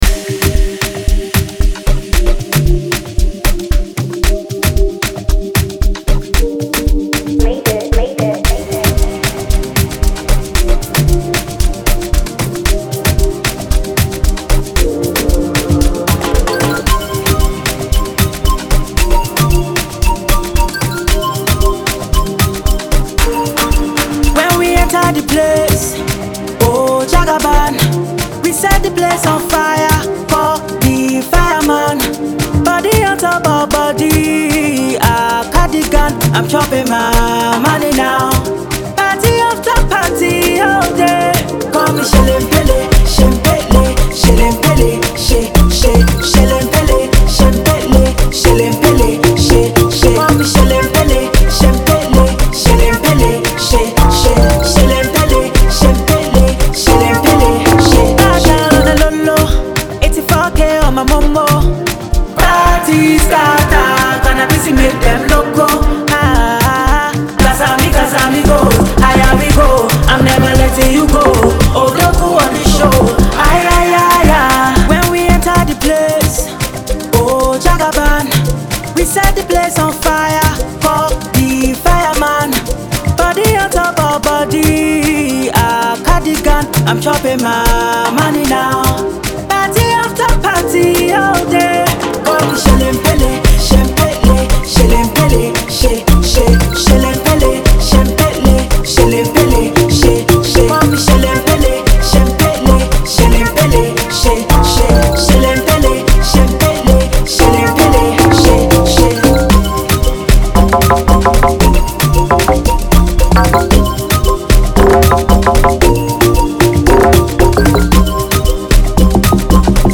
Ghanaian Afrobeat